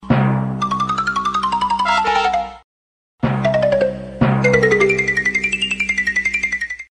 • Качество: 128, Stereo
Отличный стандартный рингтон смс